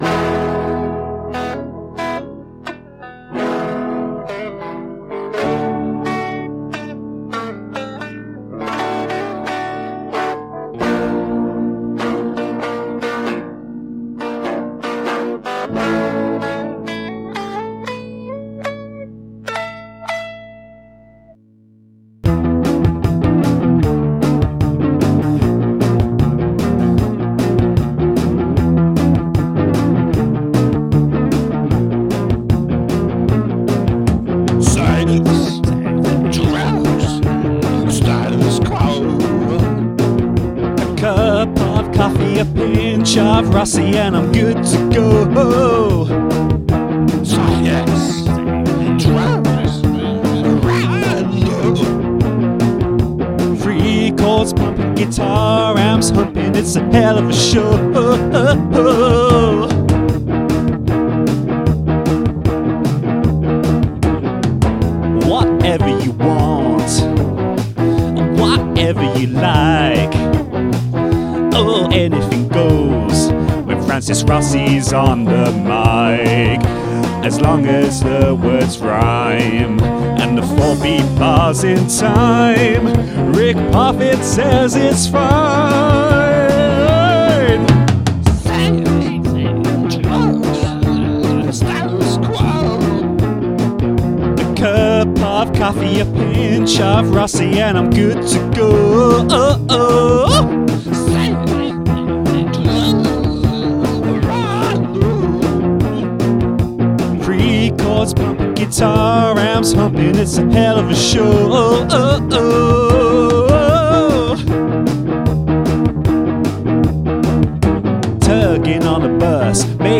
You get this waltz.
I also did the solo with ease.